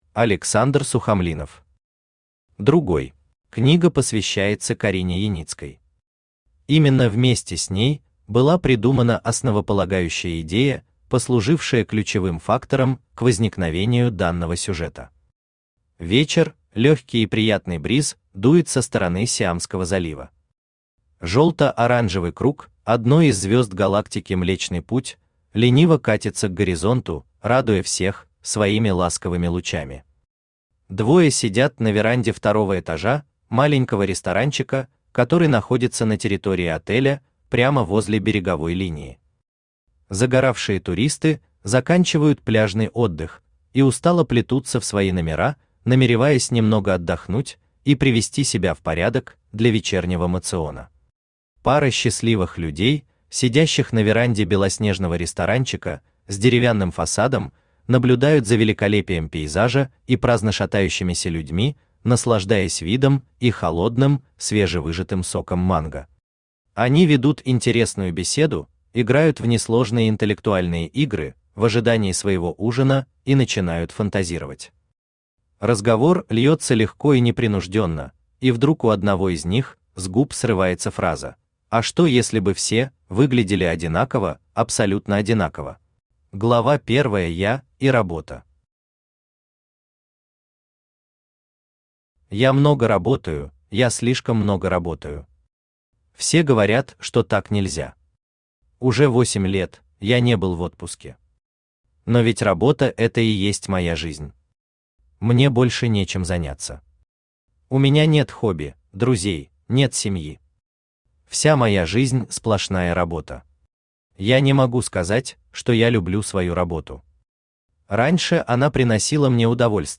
Аудиокнига Другой | Библиотека аудиокниг
Aудиокнига Другой Автор Александр Сухомлинов Читает аудиокнигу Авточтец ЛитРес.